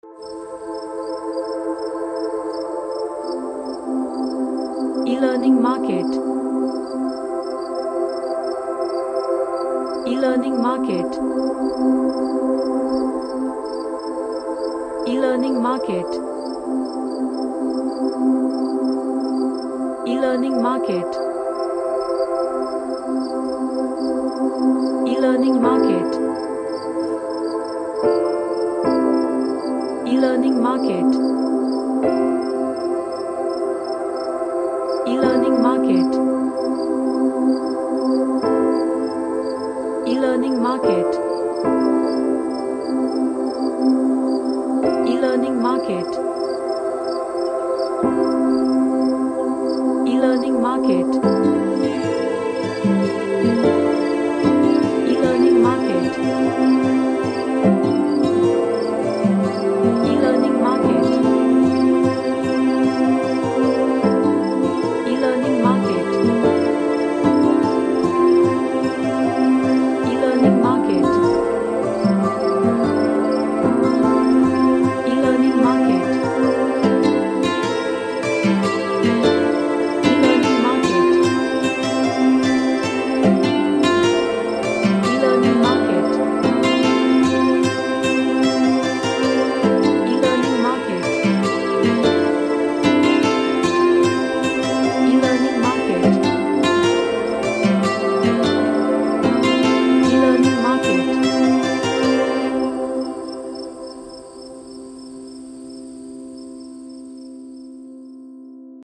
An ambient synht track
Relaxation / MeditationAmbient